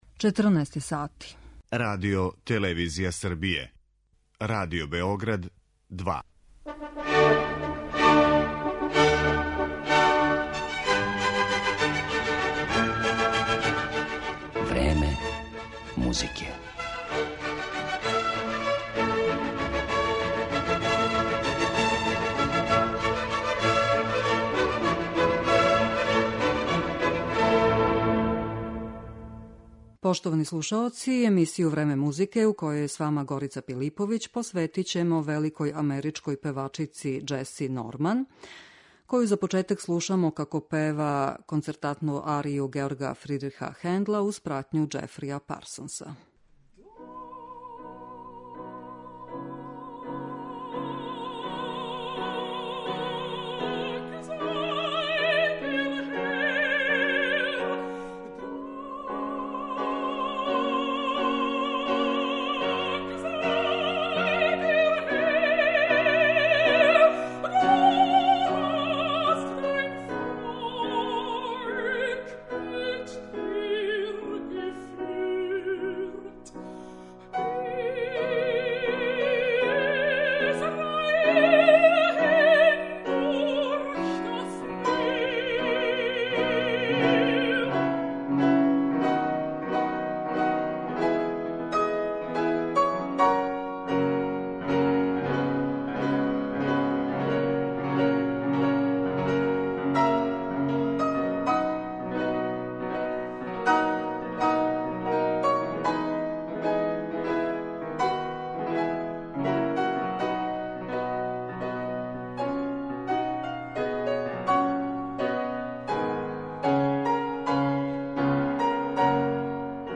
Џеси Норман је једна од најчувенијих уметница нашег доба, прослављена америчка сопранисткиња, добитница највећих светских признања.